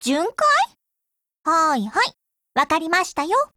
贡献 ） 协议：Copyright，其他分类： 分类:语音 、 分类:少女前线:P2000 您不可以覆盖此文件。